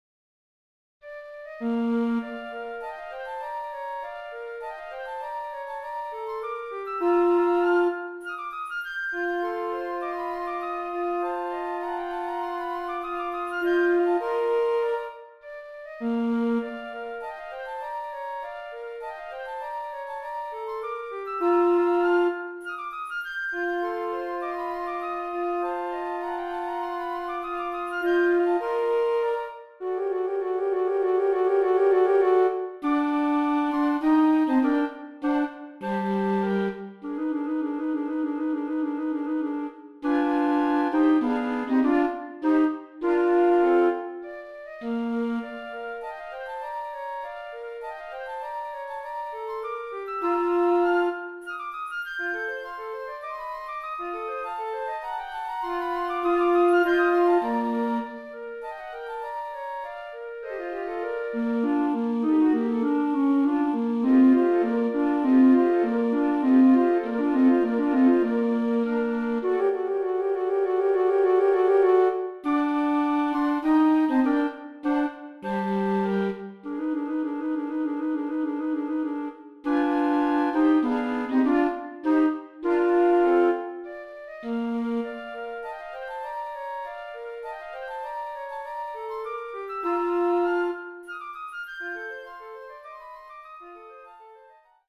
Voicing: Flute Quartet